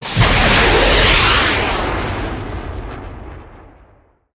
probelaunch.ogg